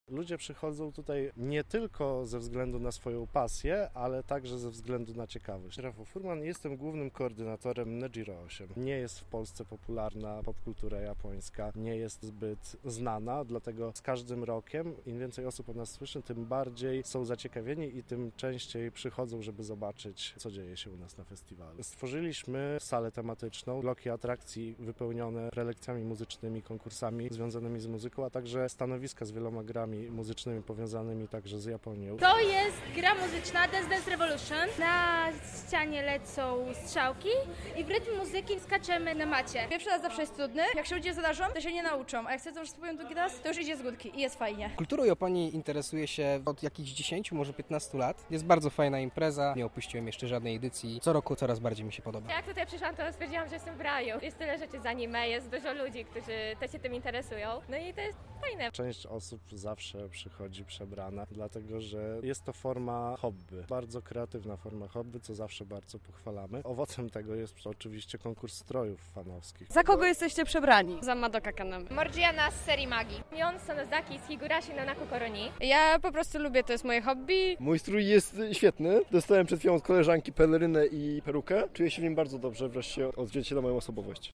Nejiro 8 ruszyło w piątek, 7 lipca o godzinie 15 w Wyższej Szkole Przedsiębiorczości i Administracji.